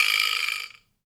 wood_vibraslap_hit_01.wav